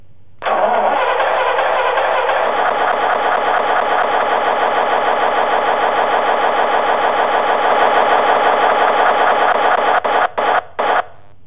The engine sounds are load sensitive being loud when accelerating, softened when coasting and softer still when slowing down.
light_engine_baguley.wav